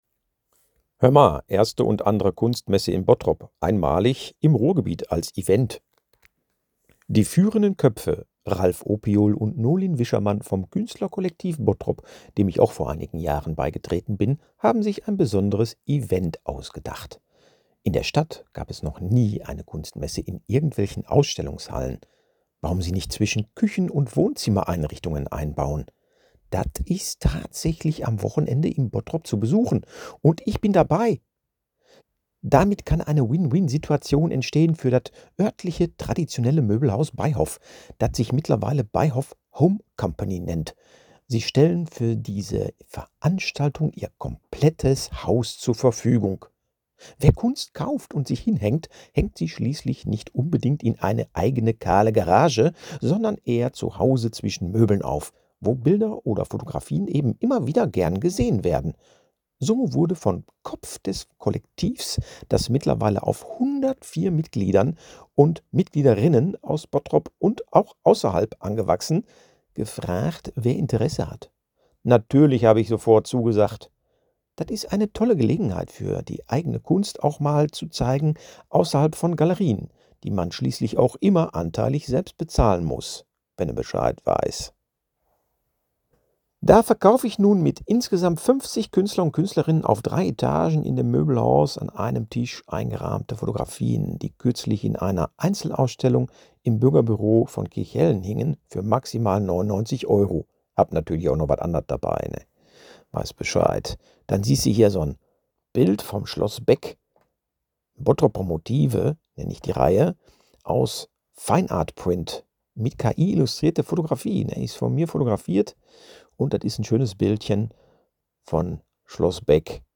Erste und andere Kunstmesse in Bottrop einmalig im Ruhrgebiet I +Hördatei in Ruhrdeutsch
Wenne den Beitrach auf Ruhrdeutsch hören möchtes:
In-Ruhrdeutsch-gelesen-Bottroper-Kunstmesse.mp3